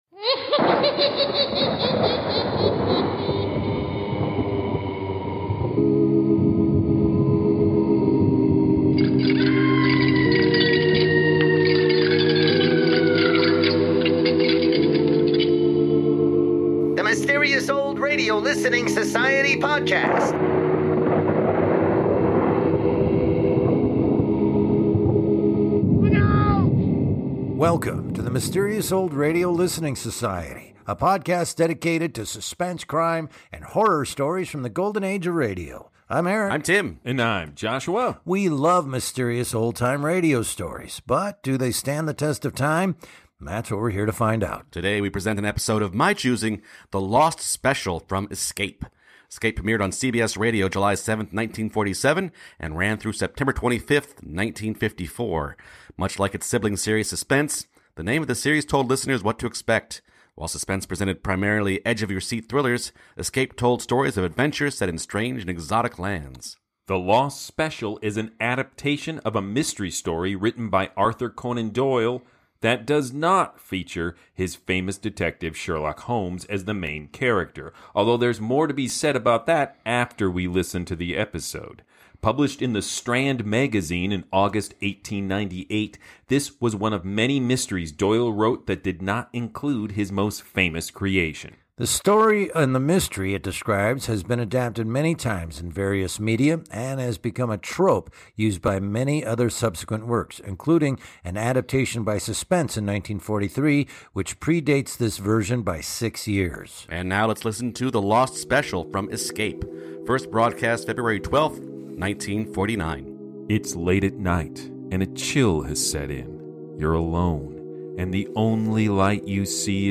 All aboard for this adaptation of an Arthur Conan Doyle mystery that does not feature Sherlock Holmes!